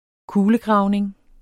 Udtale [ -ˌgʁɑwˀneŋ ]